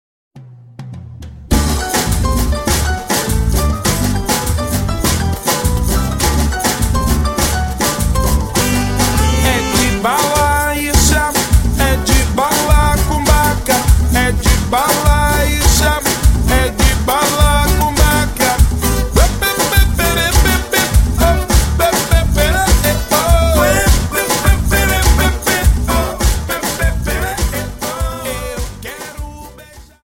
Dance: Samba 51 Song